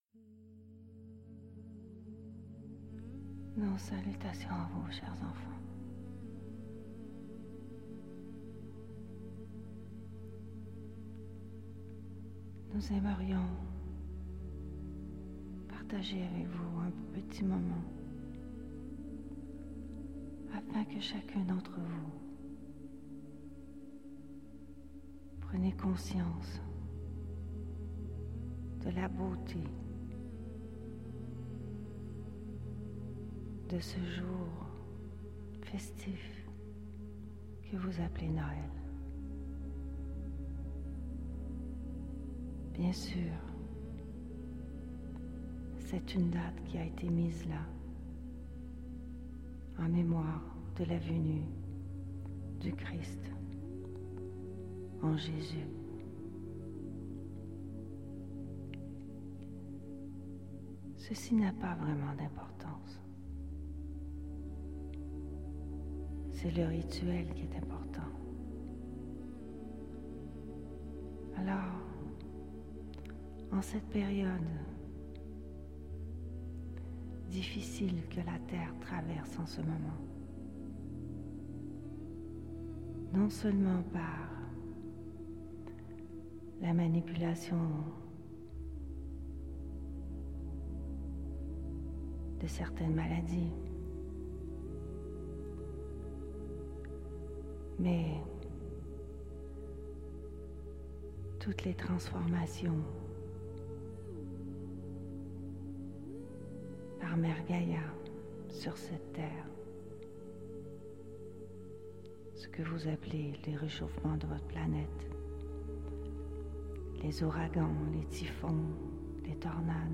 Voici le message des Êtres de Lumière, en cette période de Noël 2021.